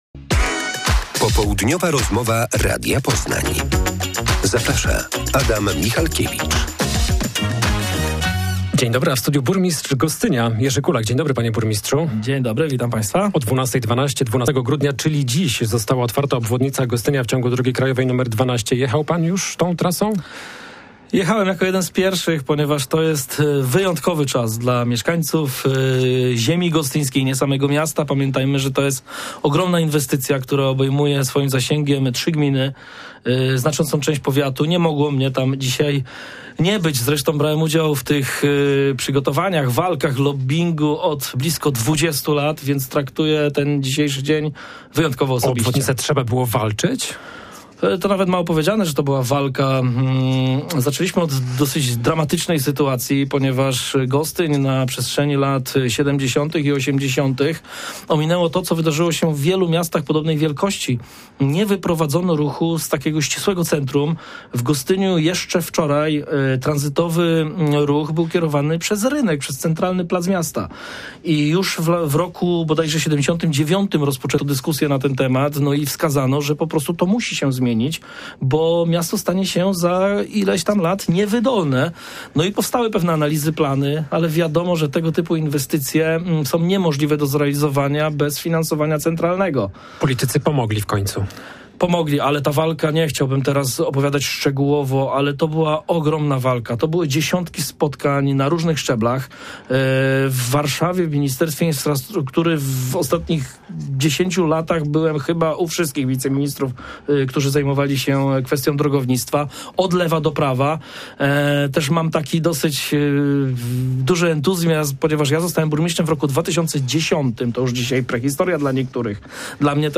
Rozmowa z burmistrzem Gostynia Jerzy Kulakiem o otwartej dziś obwodnicy miasta.